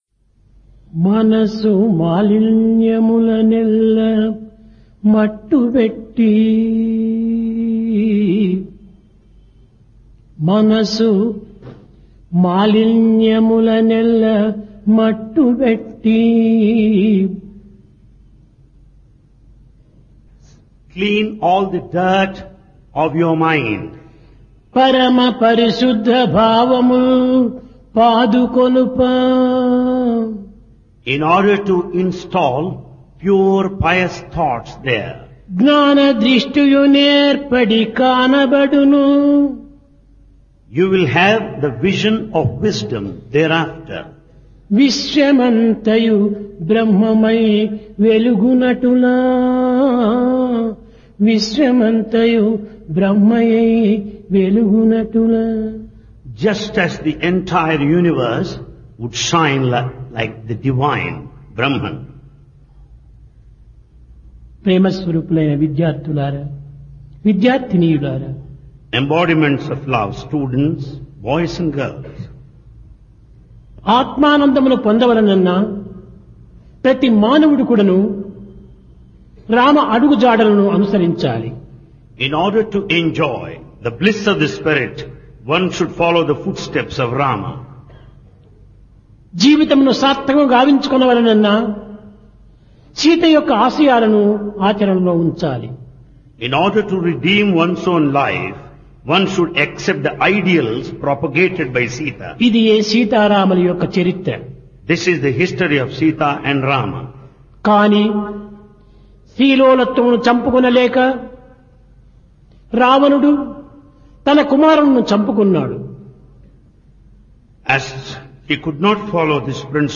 Discourse
Occasion Summer Course 1996